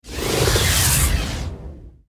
archer_skill_rapidshot_01_intro.ogg